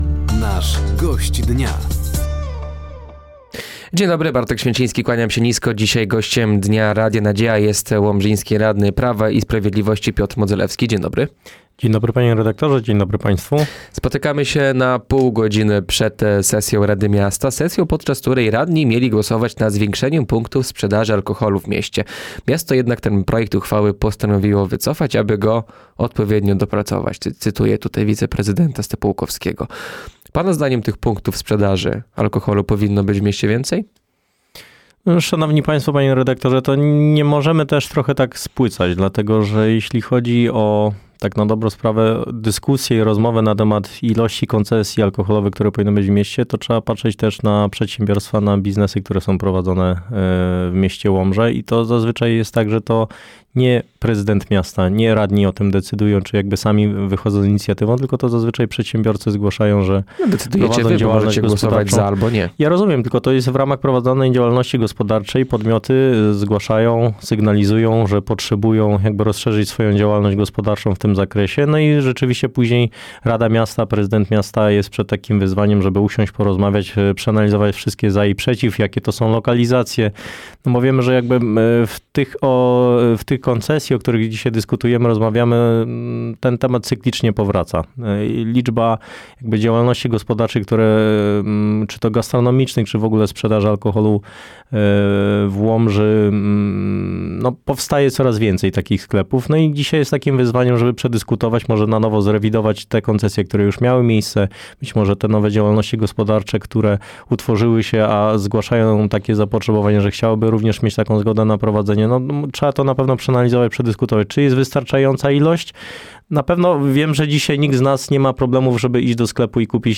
Gościem Dnia Radia Nadzieja był Piotr Modzelewski, łomżyński radny Prawa i Sprawiedliwości. Tematem rozmowy było zwiększenie punktów sprzedaży alkoholu w mieście, gospodarka śmieciowa, kolej do Łomży oraz zbliżający się kongres Prawa i Sprawiedliwości.